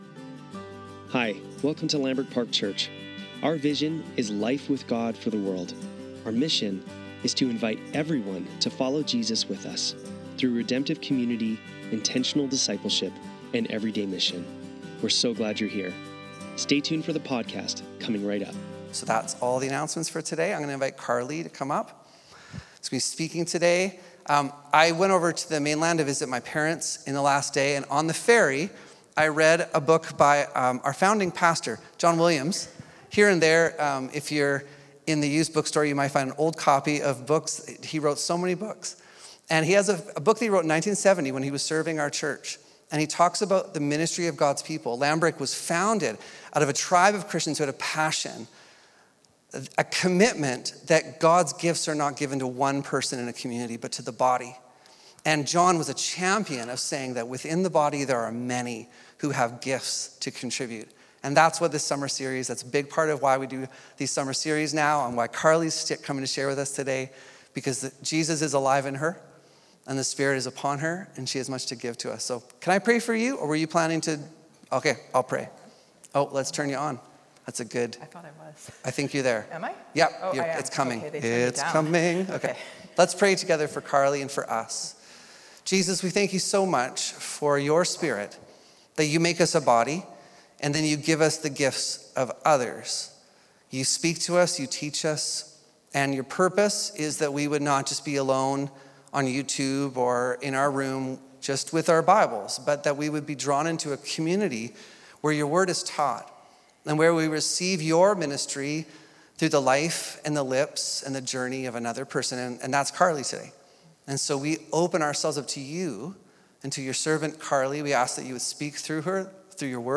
Lambrick Sermons | Lambrick Park Church
Sunday Service - August 10, 2025